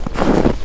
STEPS Snow, Walk 13.wav